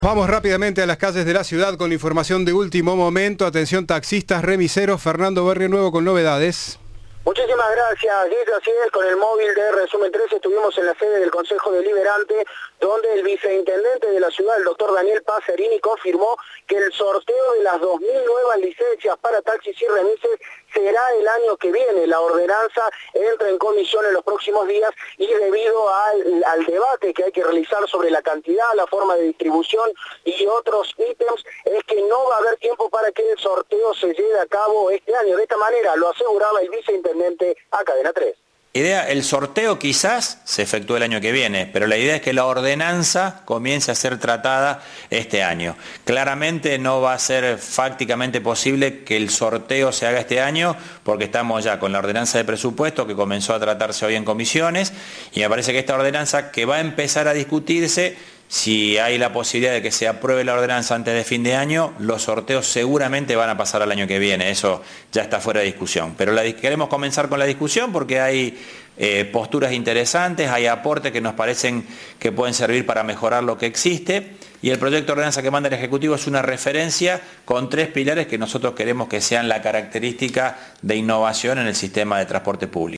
El viceintendente de la ciudad de Córdoba, Daniel Passerini, confirmó a Cadena 3 que el sorteo de las dos mil nuevas licencias para taxis y remises será el año que viene.